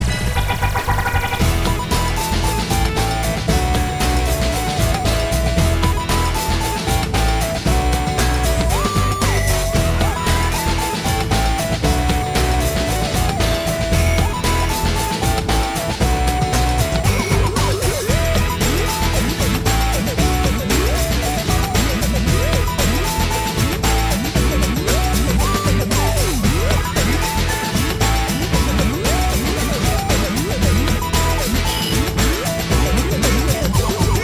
Включаю а там как на денди пиликает :-D